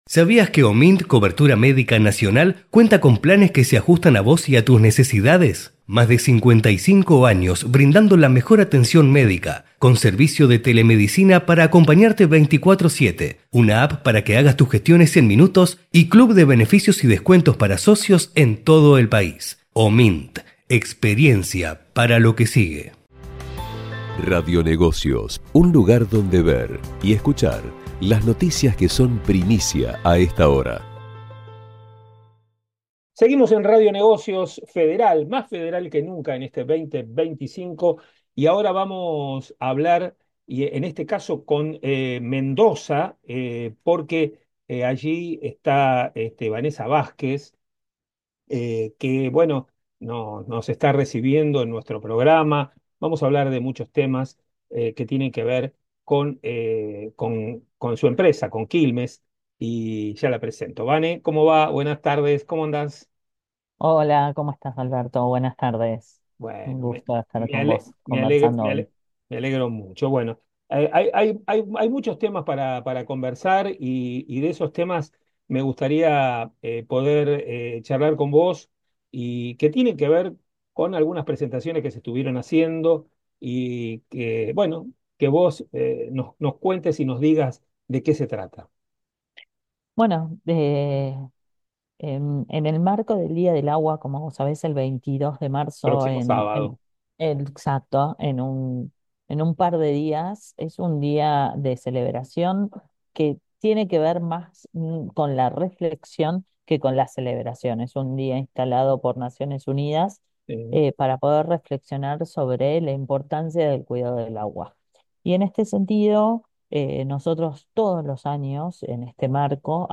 En una nueva edición de Radio Negocios Federal, conversamos